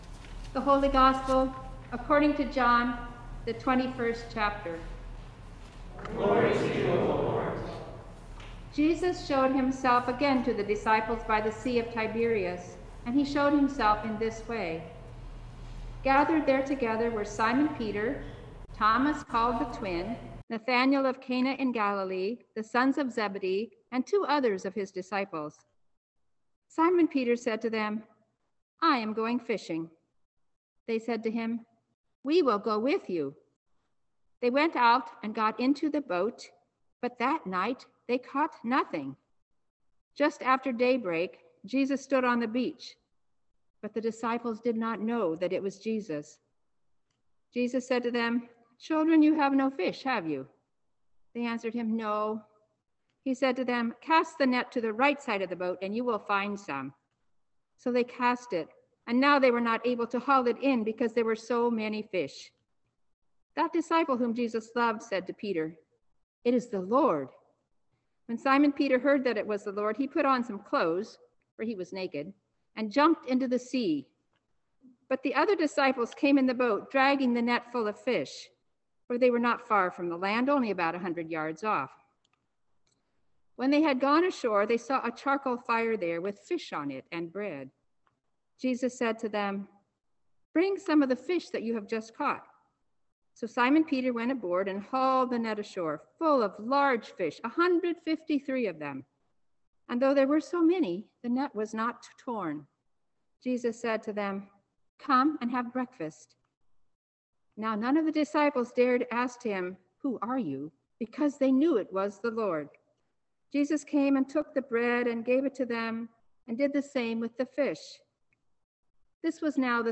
2022 at University Lutheran Church, Cambridge, Massachusetts.
Sermon for Church Music Sunday 2022